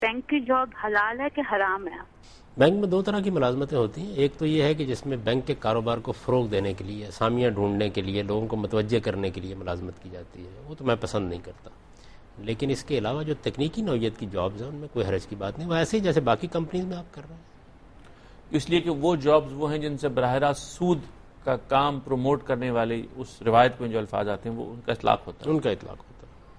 Answer to a Question by Javed Ahmad Ghamidi during a talk show "Deen o Danish" on Dunya News TV